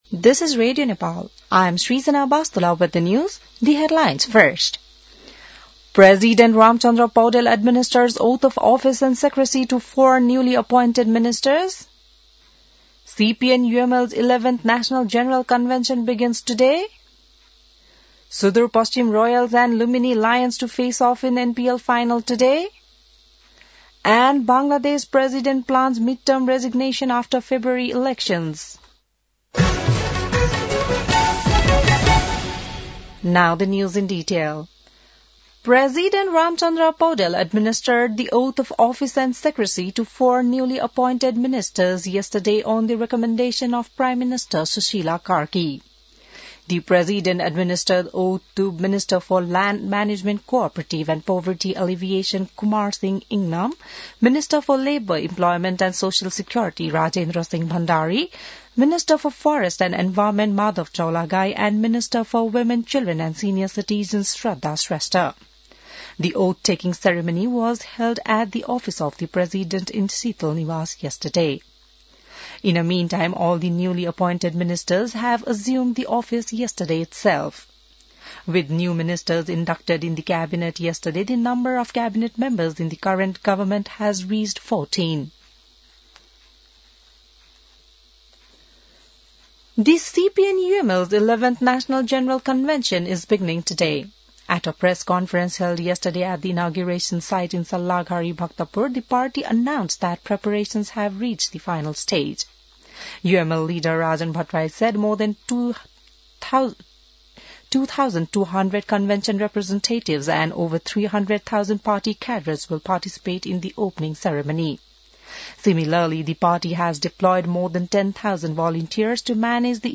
बिहान ८ बजेको अङ्ग्रेजी समाचार : २७ मंसिर , २०८२